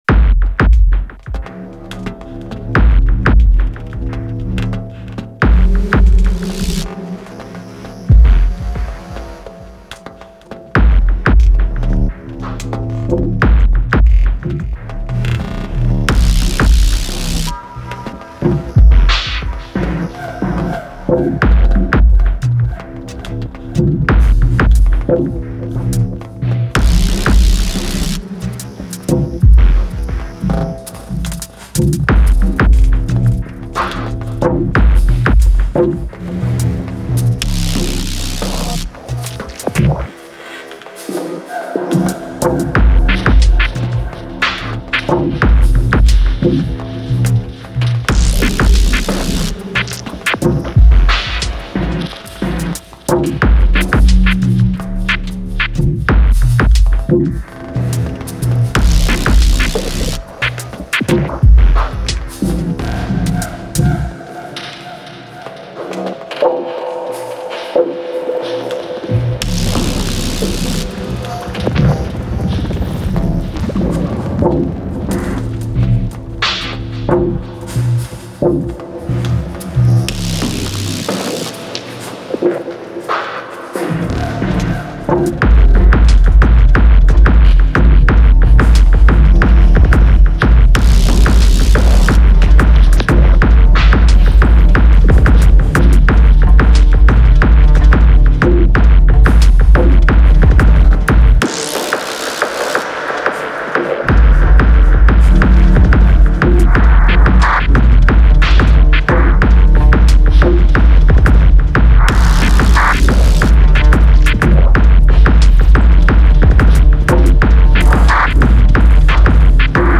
very simple thing I recorded over USB to voice memos on my phone after about an hour of playing around and figuring it out. degrader on the drums, reverb on everything, warble on the master.